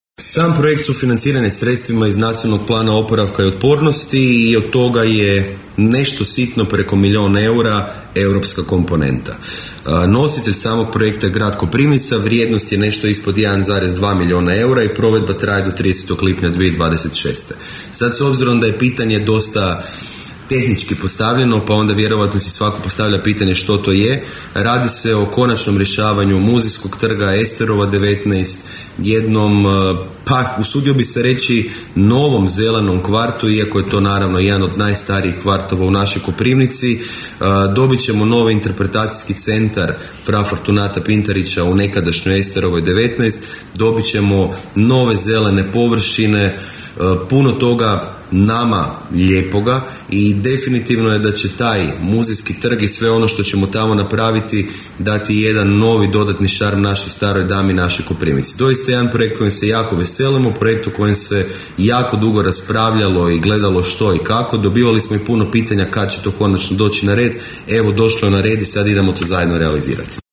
Grad Koprivnica je započeo novu fazu jednog od svojih najinovativnijih projekata. Riječ je o pilot projektu razvoja zelene infrastrukture i/ili kružnog gospodarenja prostorom i zgradama Grada Koprivnice, o kojem je za Podravski radio u emisiji Koprivničke teme više rekao gradonačelnik Koprivnice Mišel Jakšić: